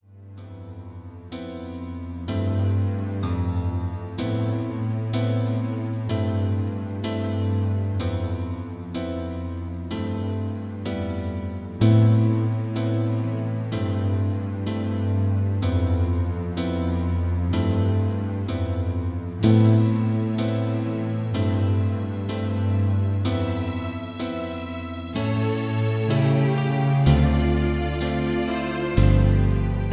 (Version-1) Karaoke MP3 Backing Tracks
Just Plain & Simply "GREAT MUSIC" (No Lyrics).
Tags: karaoke , mp3 backing tracks